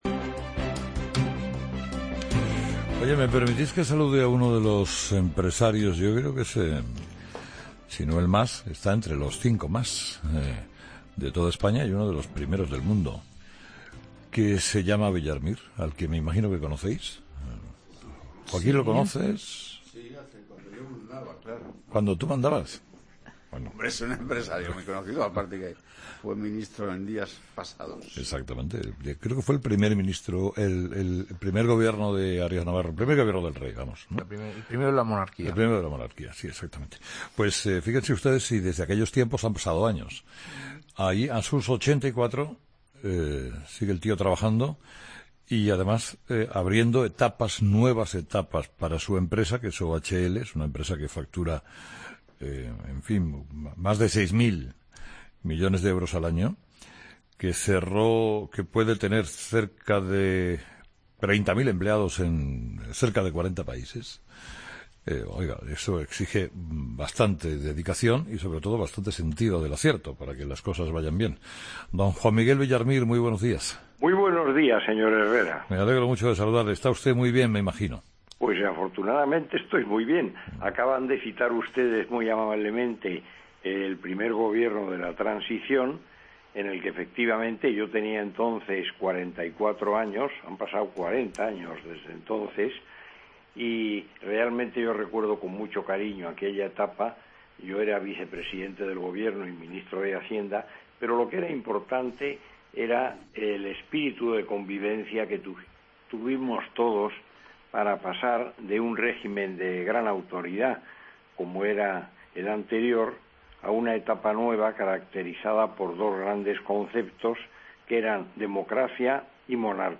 AUDIO: Escucha la entrevista a Juan Miguel Villar Mir, presidente de OHL, en 'Herrera en COPE'